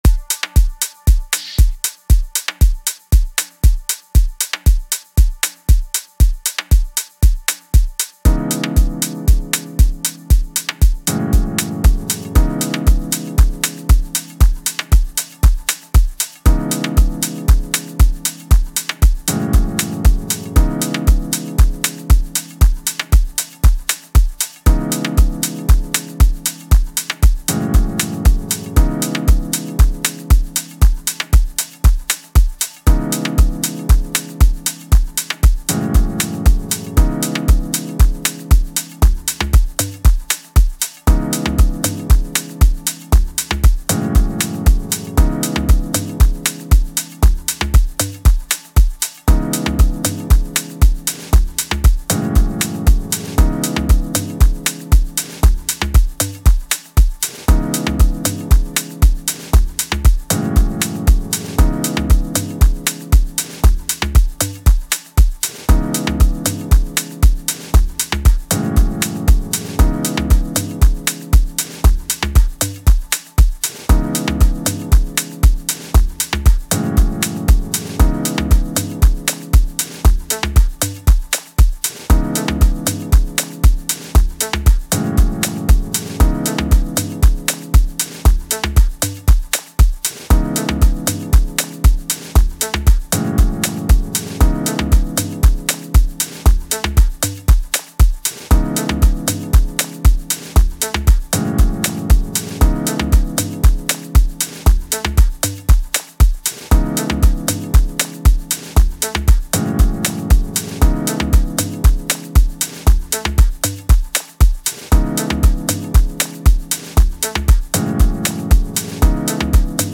Genre : Deep House